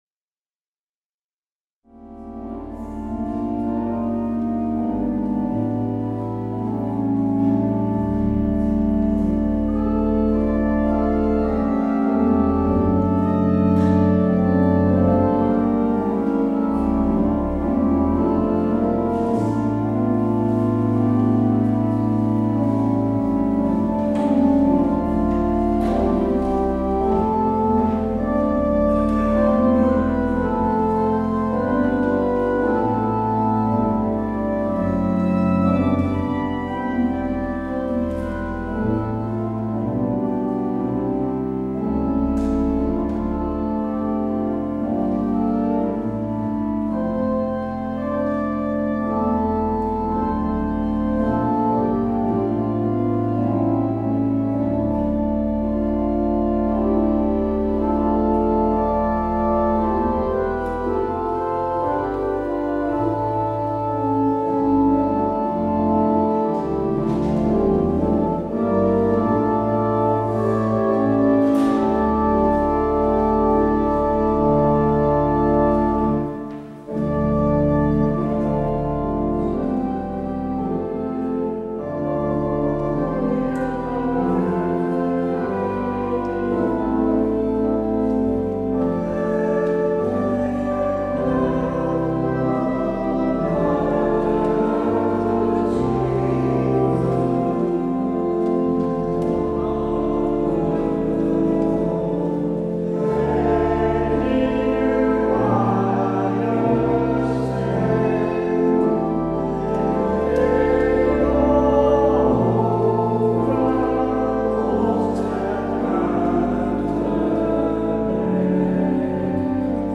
 Luister deze kerkdienst hier terug: Alle-Dag-Kerk 13 februari 2024 Alle-Dag-Kerk https
Het openingslied is: Lied 526: 3 en 4.